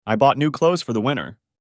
Portanto, a pronúncia será com som de /z/, ou seja, /klouz/.